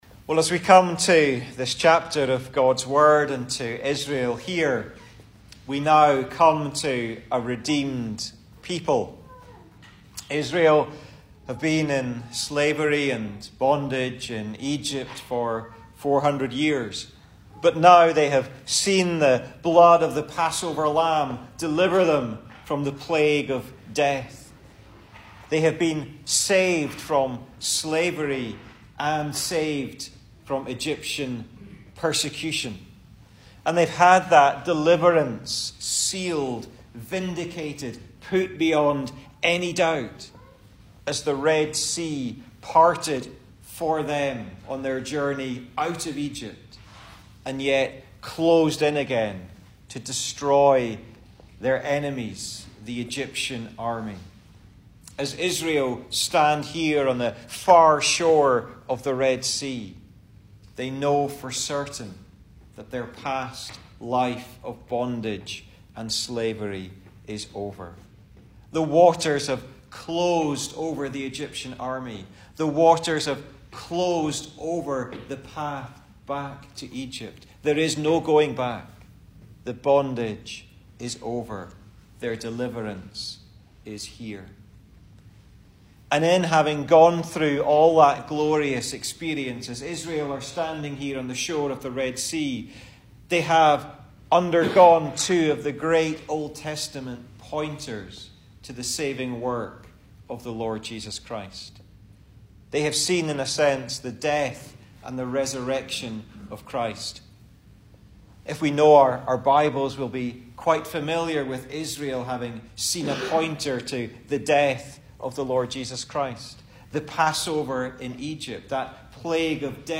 2022 Service Type: Sunday Morning Speaker
Single Sermons